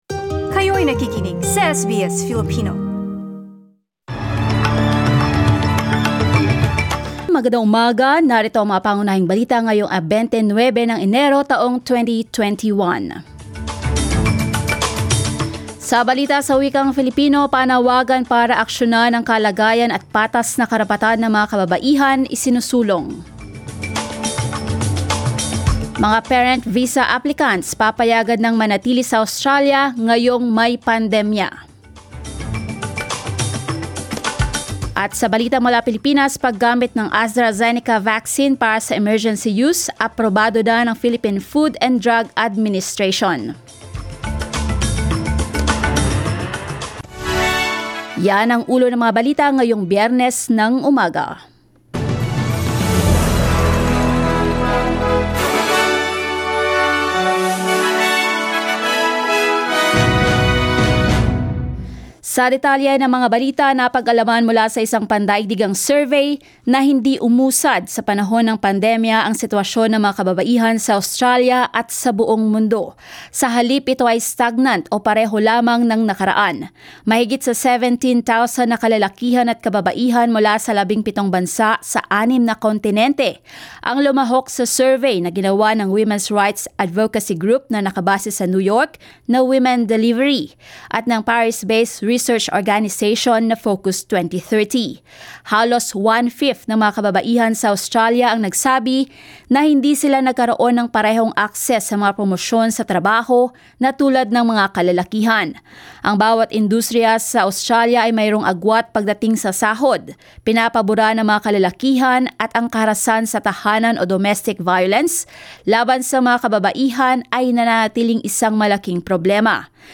SBS News in Filipino, Friday 29 January